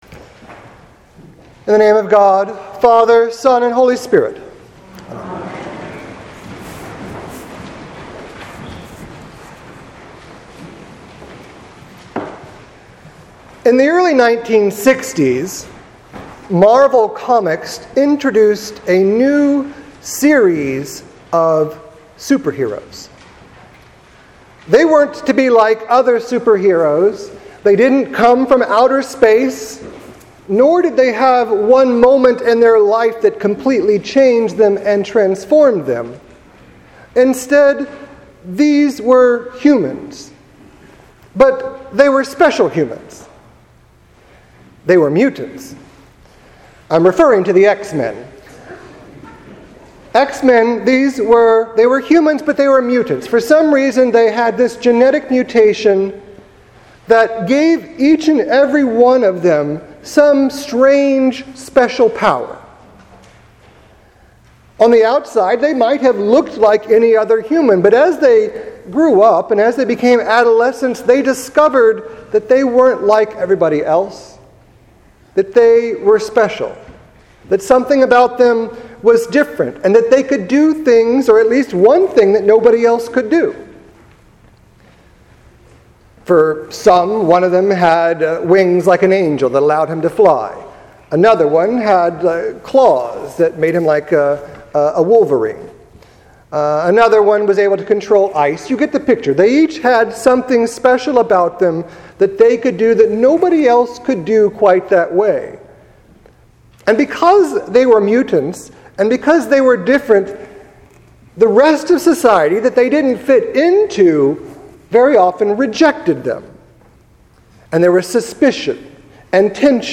Sermon for The Feast of the Holy Name 2017